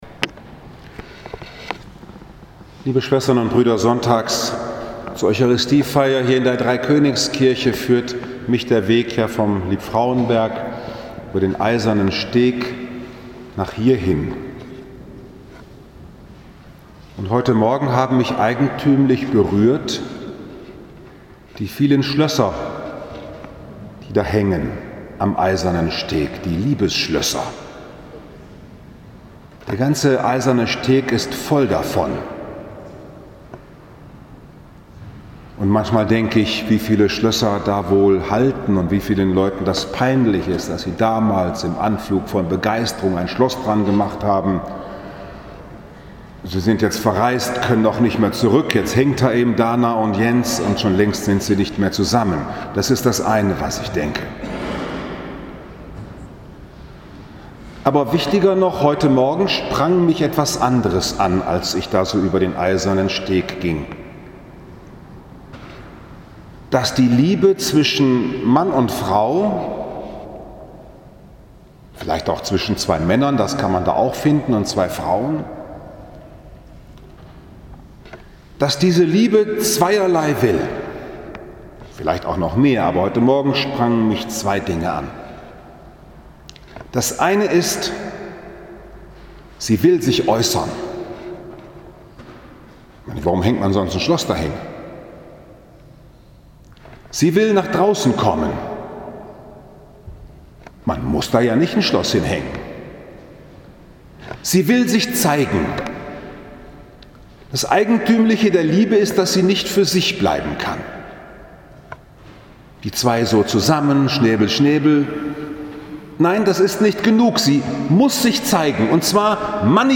Das Liebesschloss Gottes Liebe, die sich ausdrücken will und festmachen will 21. April 2019, 12.30 Uhr Dreikönigskirche Frankfurt am Main, Ostersonntag